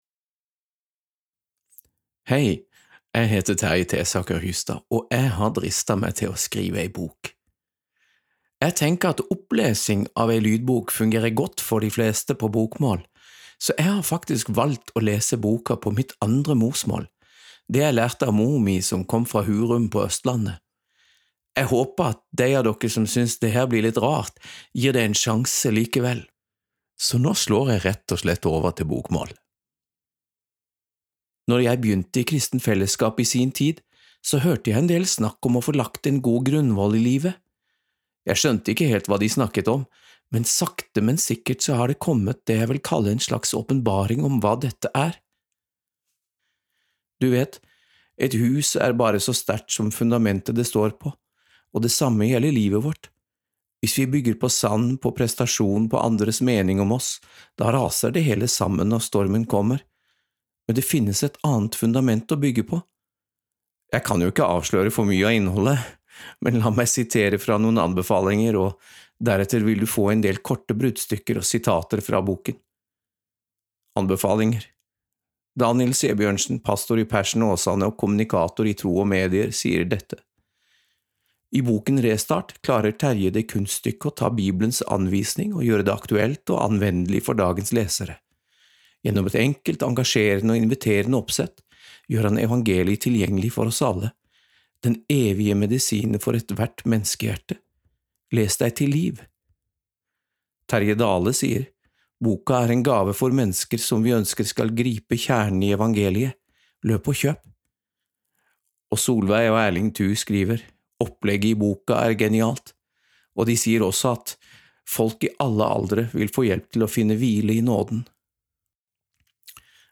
Restart – Lydbok